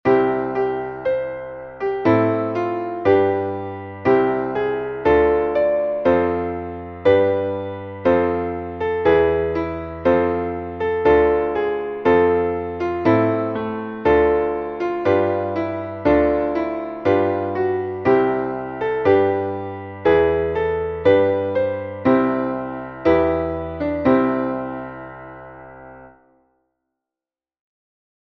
Traditionelles Neujahrslied